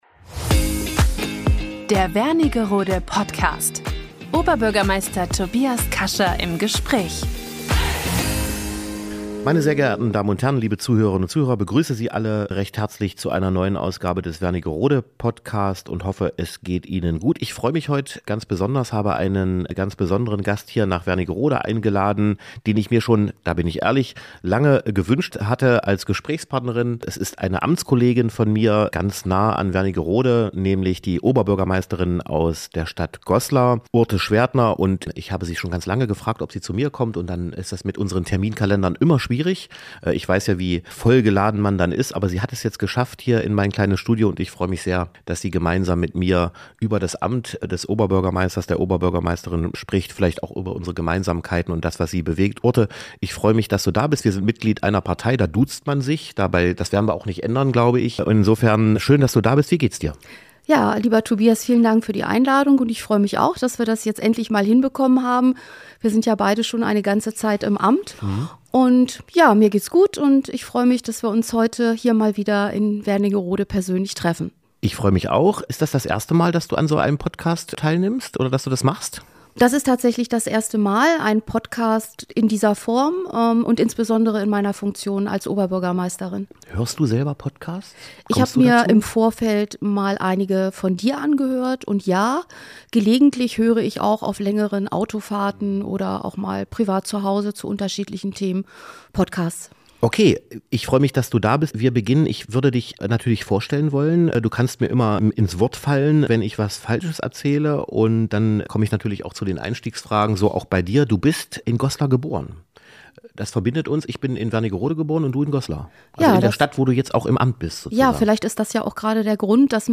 In dieser Folge spricht Oberbürgermeister Tobias Kascha mit Urte Schwerdtner.